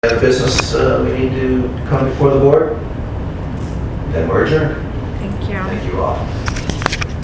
Public Meetings, Zoning
Notice, Meeting, Hearing